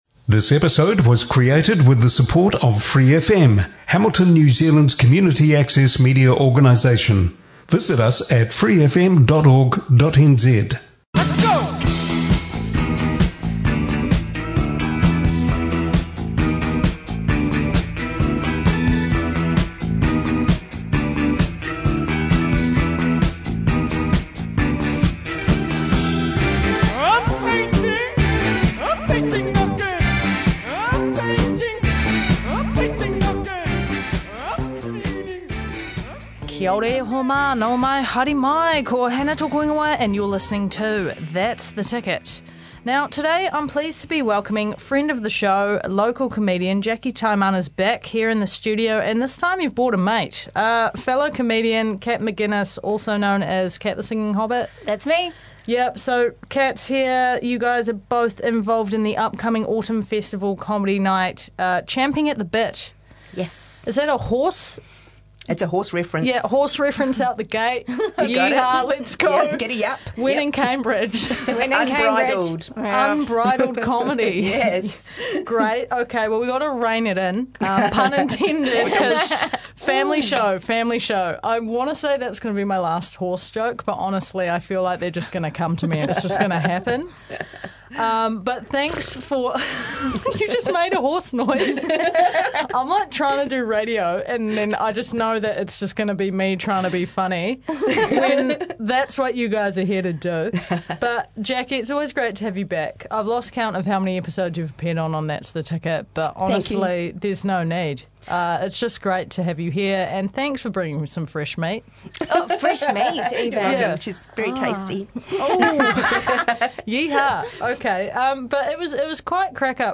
Interviews with local creatives, whats on guides and even the odd performance live on air...